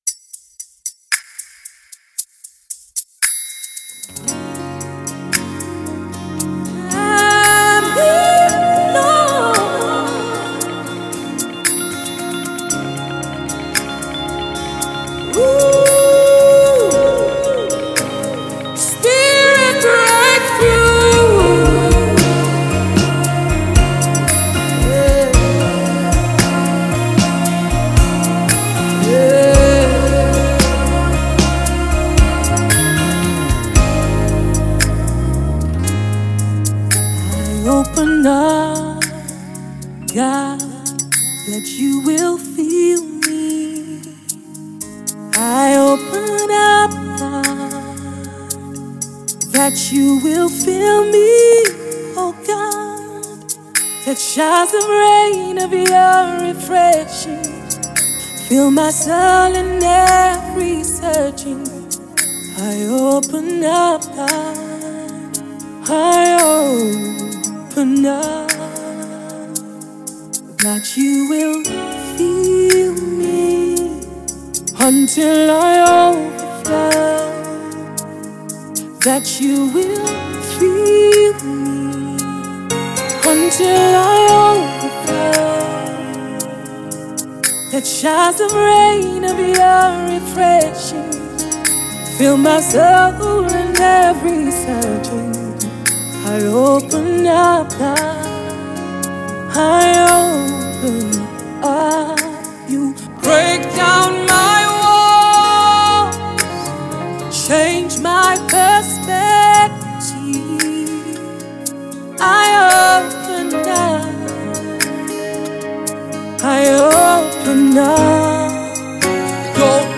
Gospel music
About the inspiration behind the Soul stirring new single